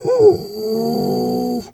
bear_pain_whimper_10.wav